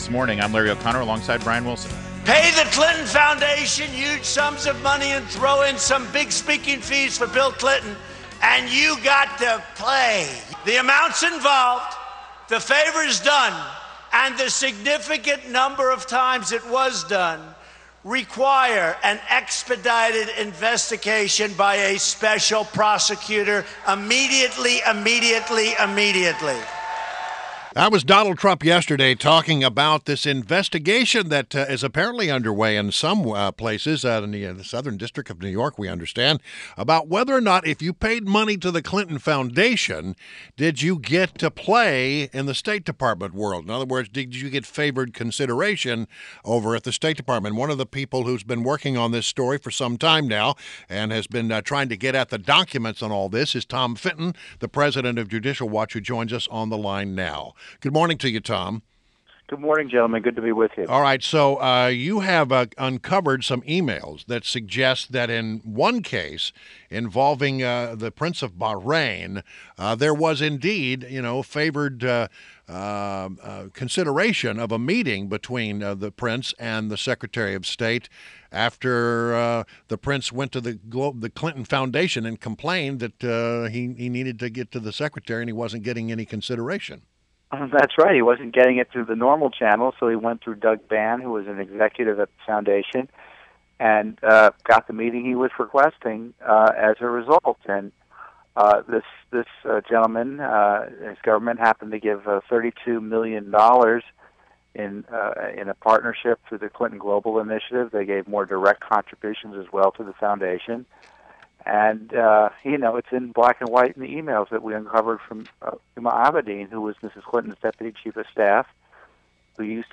WMAL Interview - Judicial Watch's TOM FITTON - 08.23.16